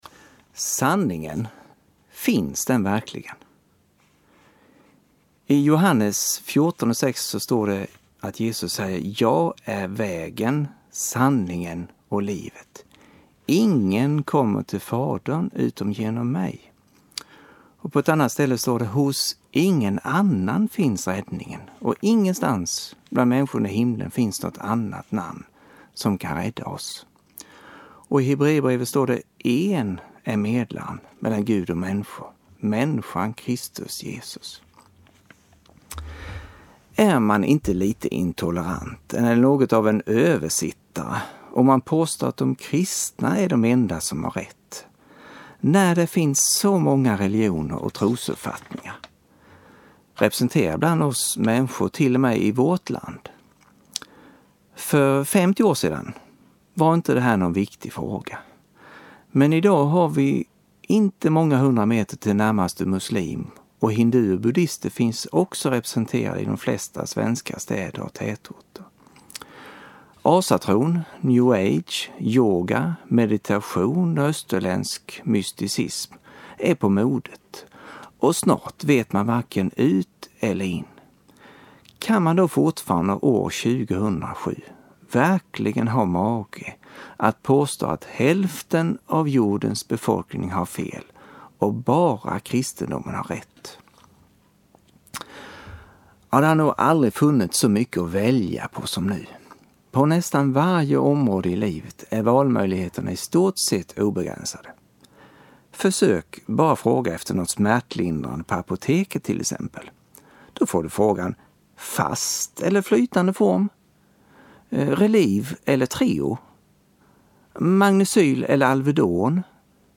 PREDIKNINGAR | Bergmans Media, Bankeryd